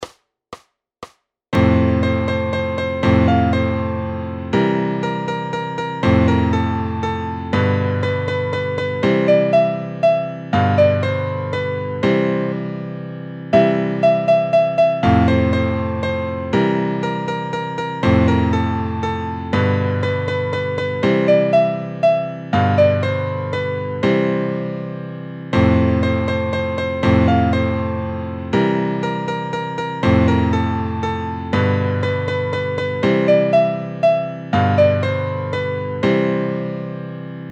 Noty na snadný sólo klavír & AUDIO.
Aranžmá Noty na snadný sólo klavír
Hudební žánr Klasický